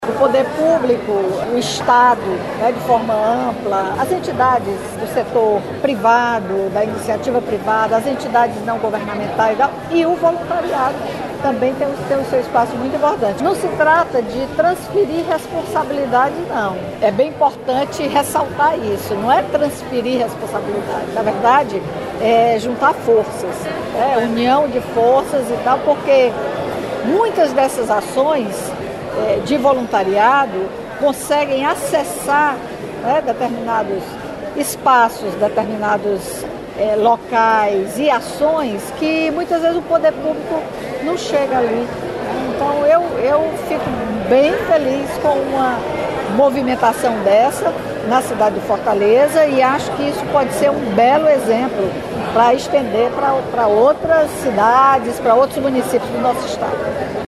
A solenidade apresentou uma nova plataforma digital que busca conectar Organizações Não Governamentais (ONGs) e voluntários para construção de uma rede de solidariedade. A ocasião, que aconteceu no auditório da Federação das Indústrias do Estado do Ceará (FIEC), reuniu líderes e voluntários de causas diversas.